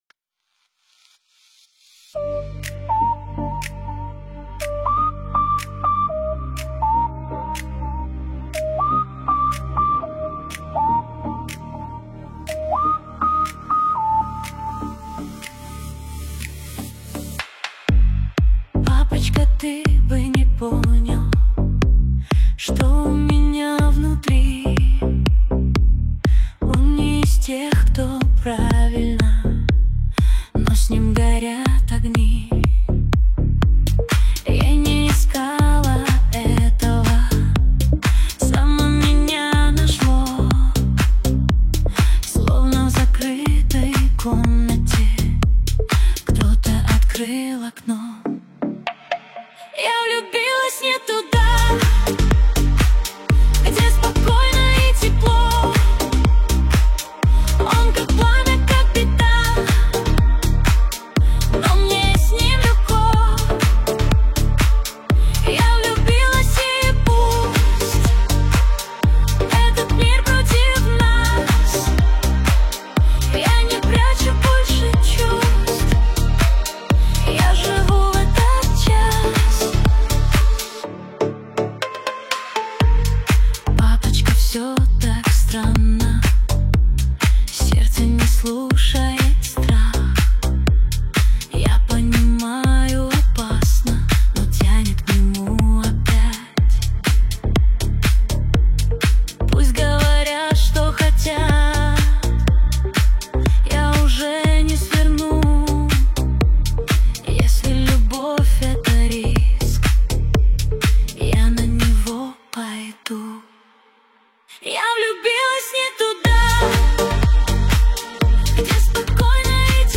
Новинки русской музыки